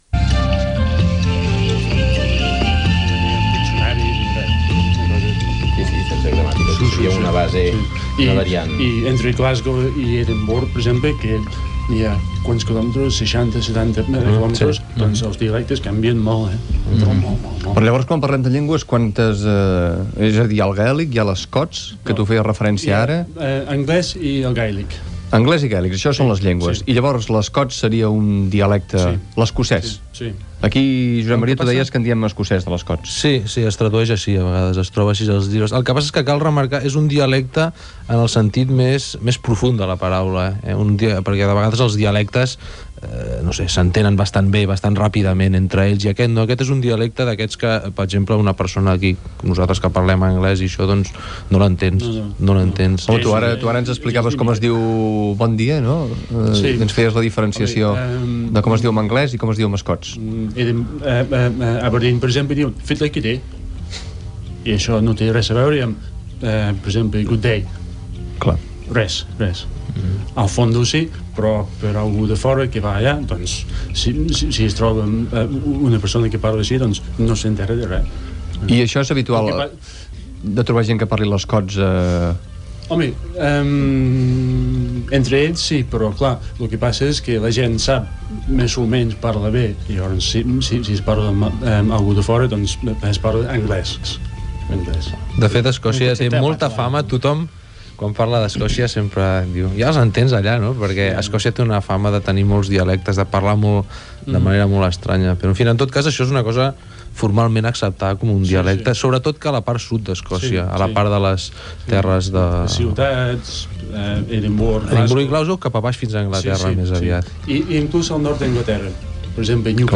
Explicacions sobre Escòcia i els seus dialectes, presentació dels invitats a la tertúlia i indicatiu del programa
Entreteniment